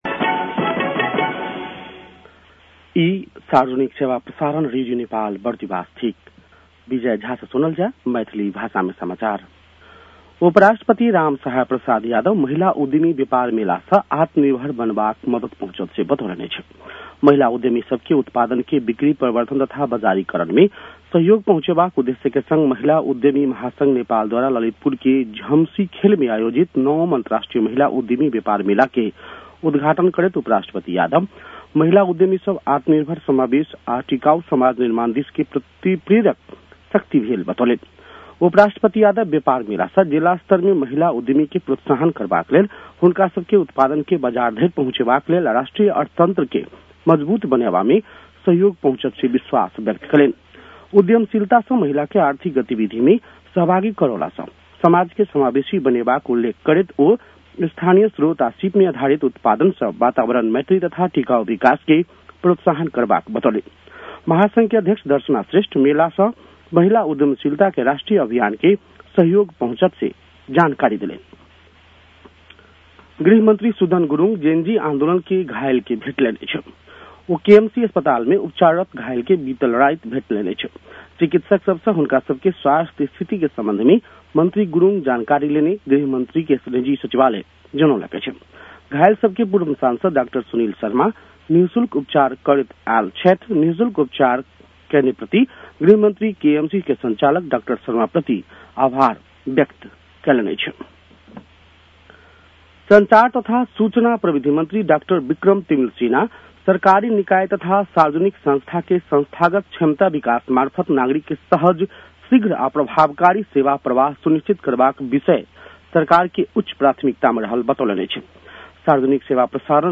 मैथिली भाषामा समाचार : २० चैत , २०८२
Maithali-news-12-20.mp3